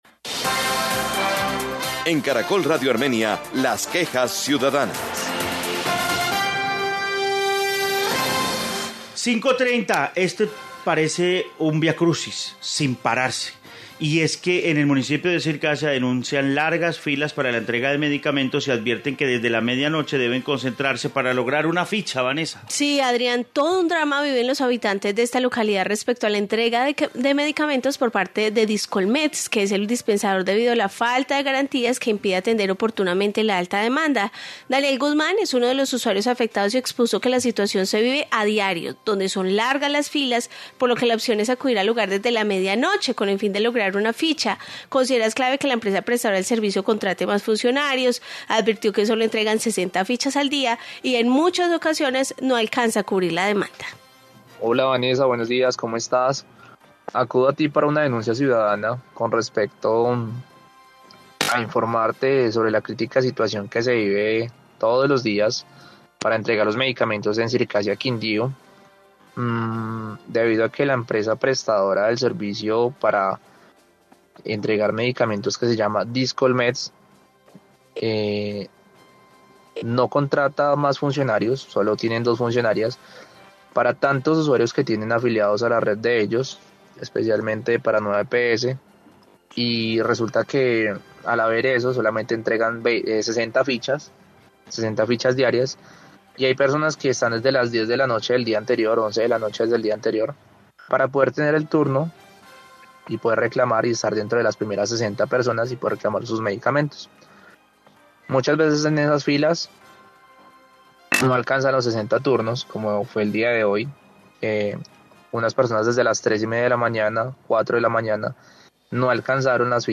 Informe medicamentos Circasia, Quindío